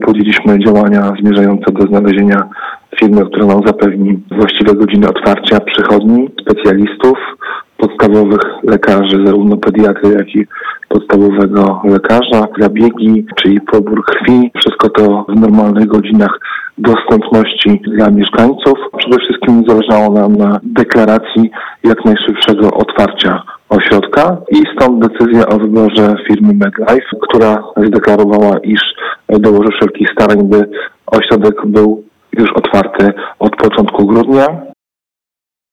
Na nowego operatora wybrano firmę MedLife. Mówi Wójt Gminy Słupno Marcin Zawadka.